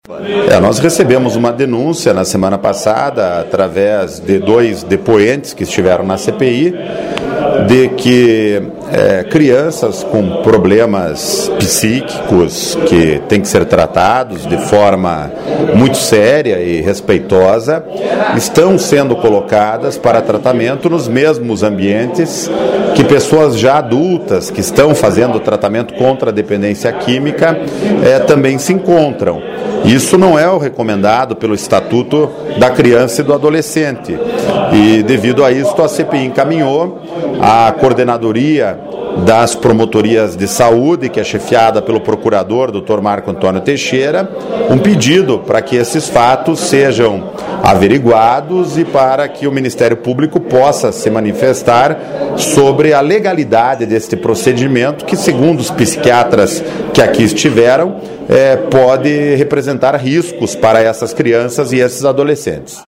Acompanhe a sonora do deputado: